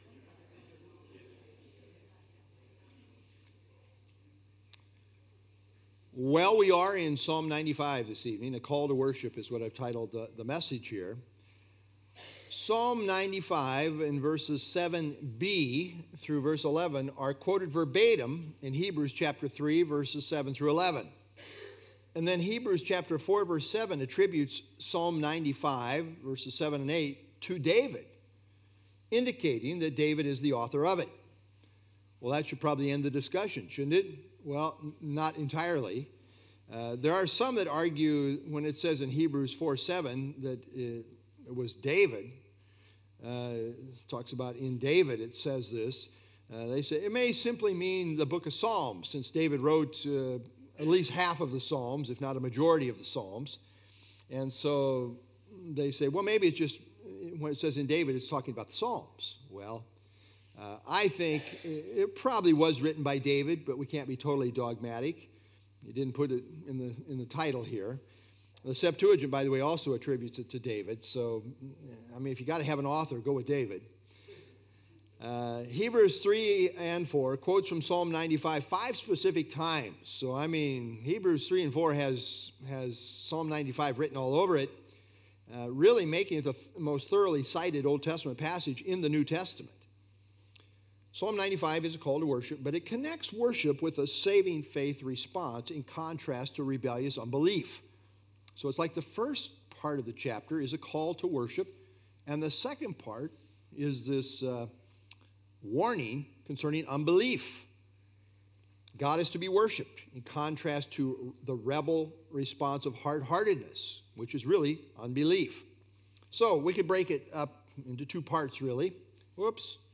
Download FilesPsalm-95-Sermon-Feb-1-2026Psalm 95 - Sermon - Feb 1 2026Psalm 95